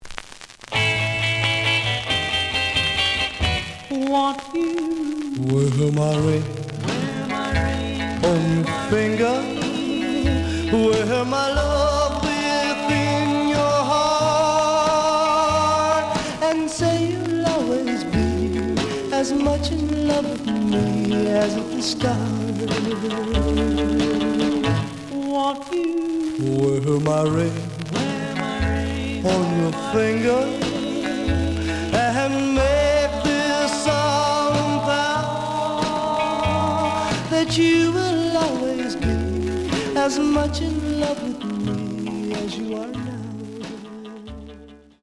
試聴は実際のレコードから録音しています。
●Genre: Rhythm And Blues / Rock 'n' Roll
●Record Grading: G+ (両面のラベルにダメージ。A面のラベルに書き込み。盤に若干の歪み。プレイOK。)